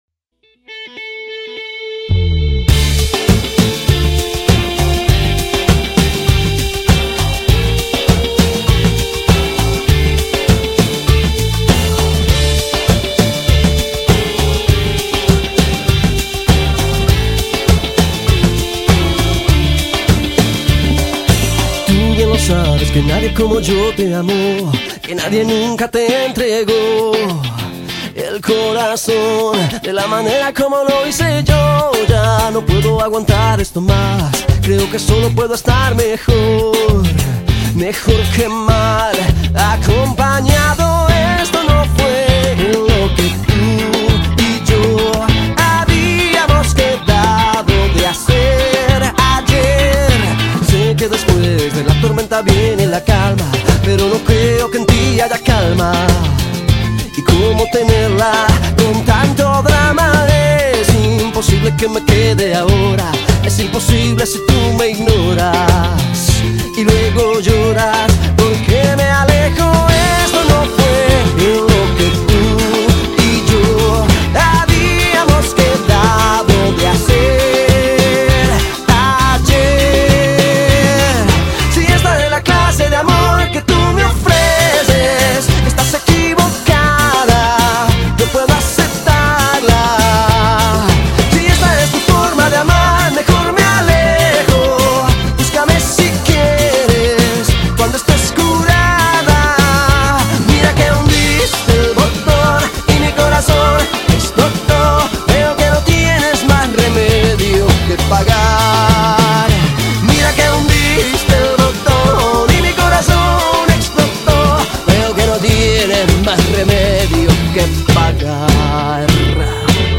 拉丁代表着浪漫热情